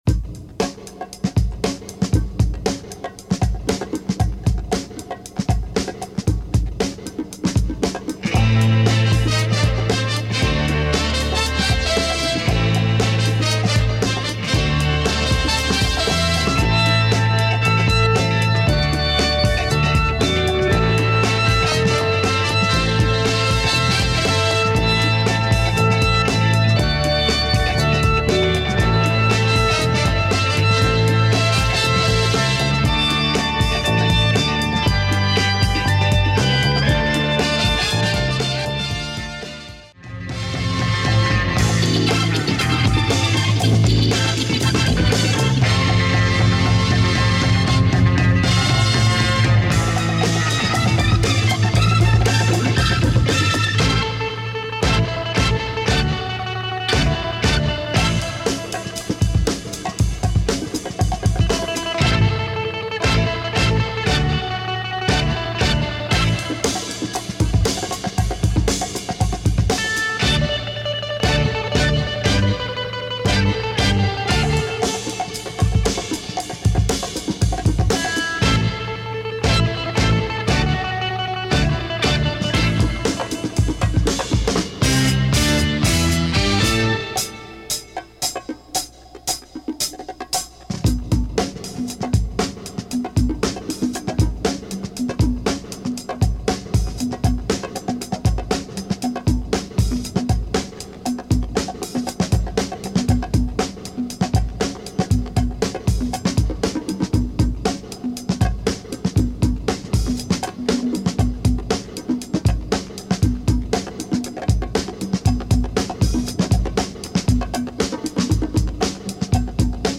Crucial bongo groove, cult album !